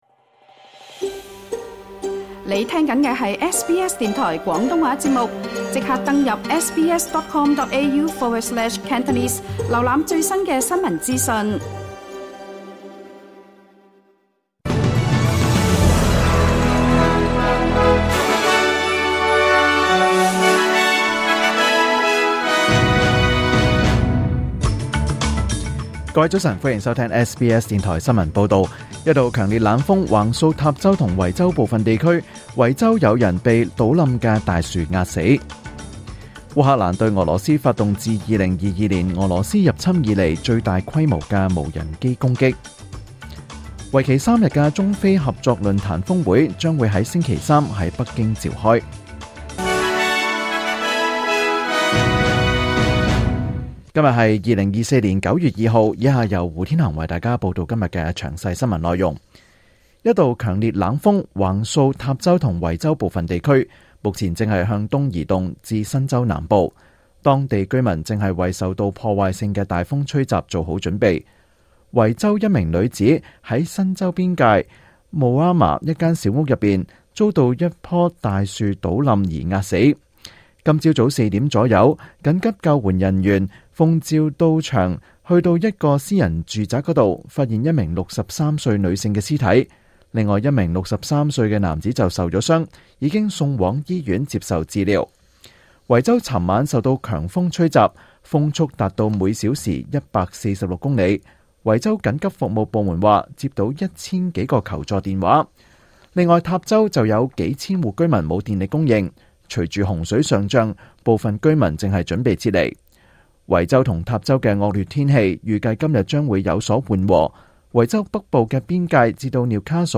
2024年9月2日SBS廣東話節目詳盡早晨新聞報道。